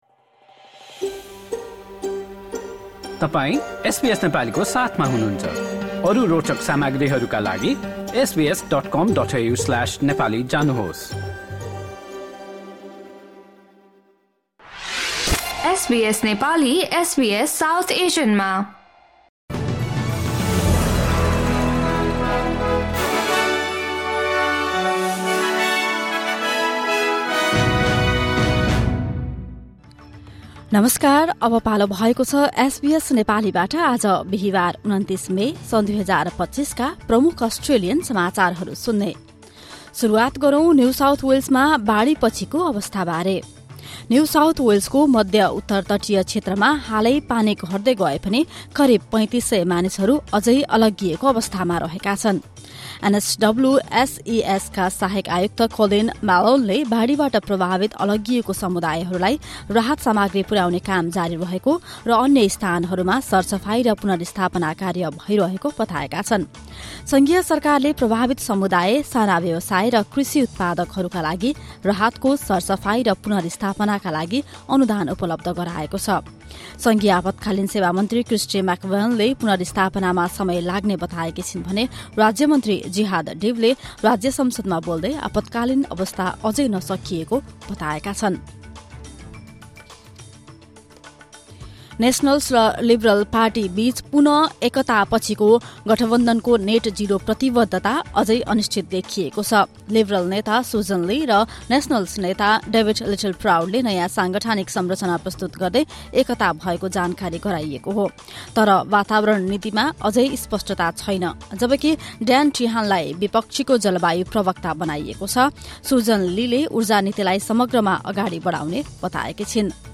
एसबीएस नेपाली प्रमुख अस्ट्रेलियन समाचार: बिहीवार, २९ मे २०२५